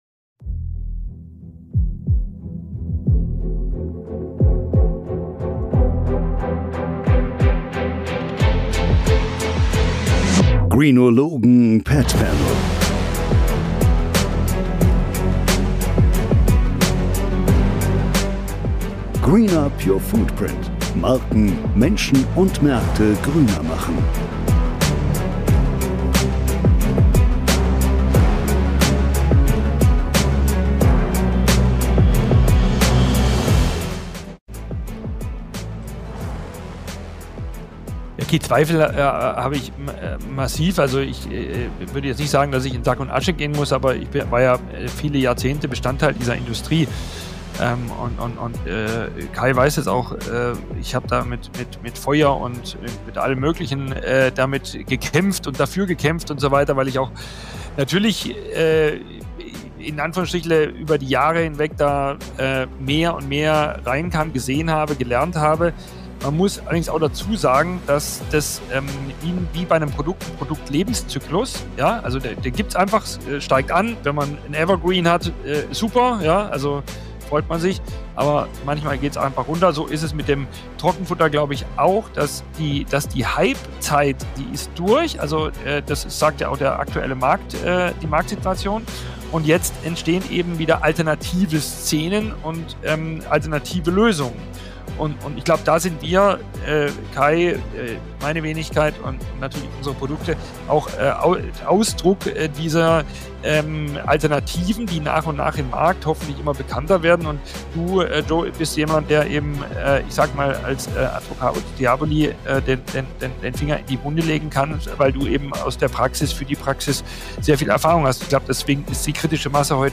Ein ehrlicher Talk über Jahrzehnte gelebte Praxis, Rückschläge, Wandel – und warum Zweifel manchmal der einzige Weg zu echter Veränderung ist.